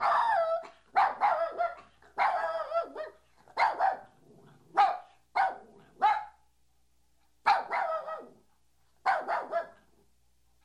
PERRO-LADRANDO.mp3